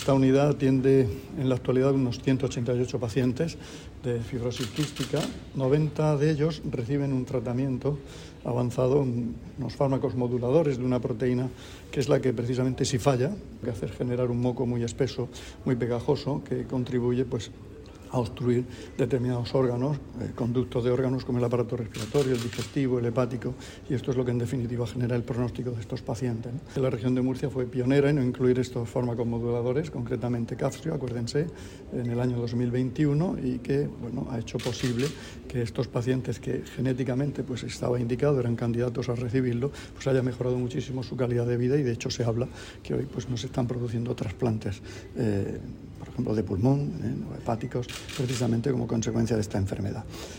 Sonido/ Declaraciones del consejero de Salud, Juan José Pedreño, sobre la atención a los pacientes con fibrosis quística.